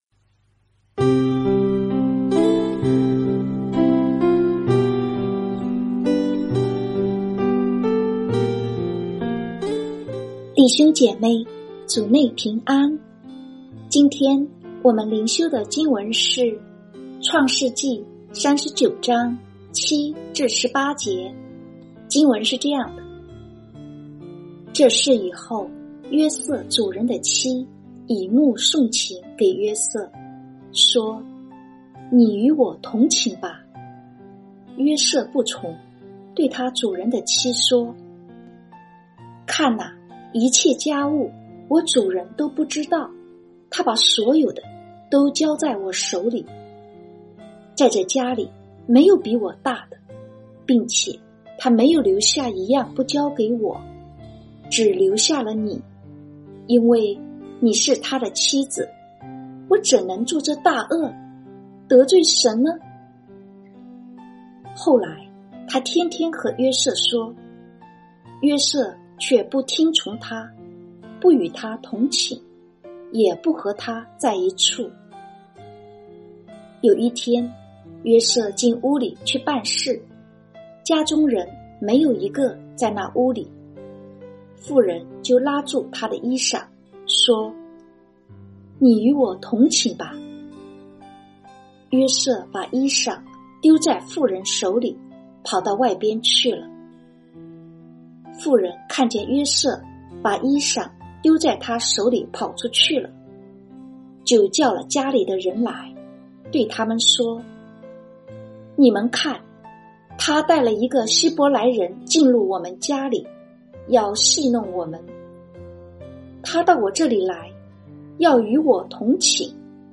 這是把通讀整卷創世記和每天靈修結合起來的一個計劃。每天閱讀一段經文，聆聽牧者的靈修分享，您自己也思考和默想，神藉著今天的經文對我說什麼，並且用禱告來回應當天的經文和信息。